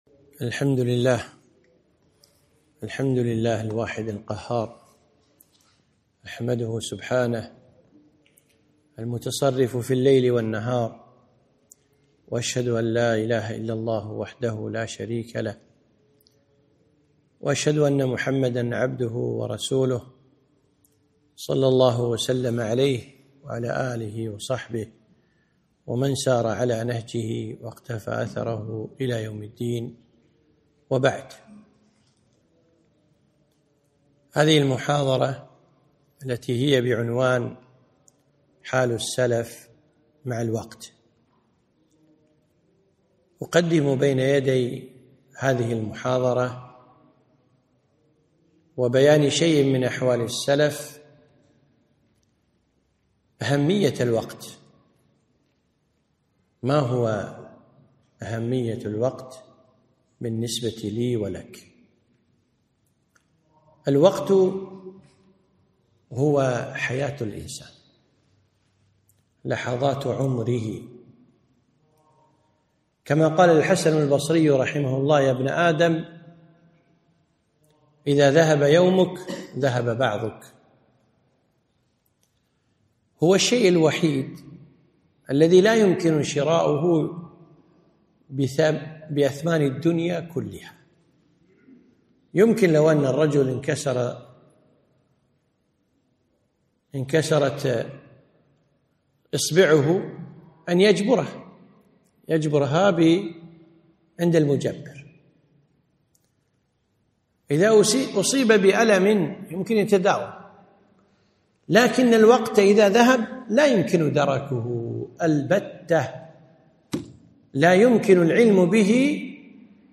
محاضرة - حال السلف مع الوقت